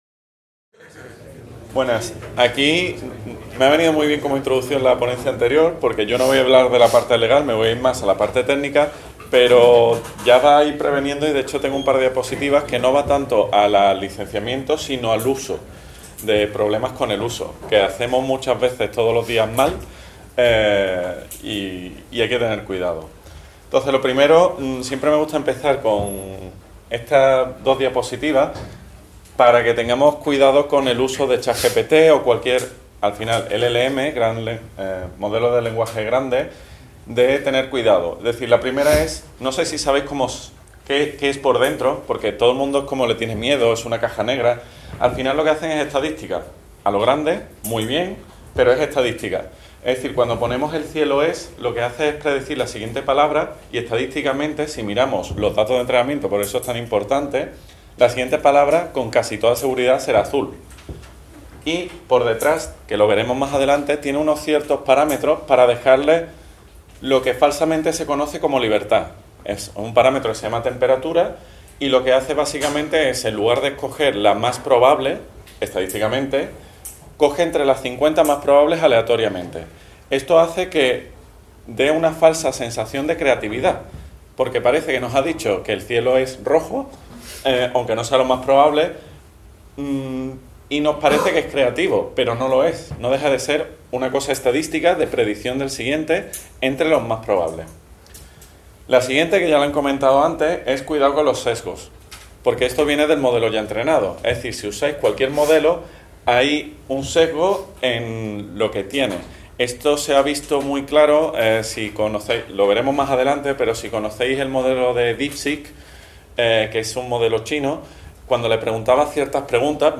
en el marc de les 18enes Jornades de SIG Lliure 2025 organitzades pel SIGTE de la Universitat de Girona. En aquesta ponència es presenta com la intel.ligència artificial pot transformar la interacció amb dades geoespacials.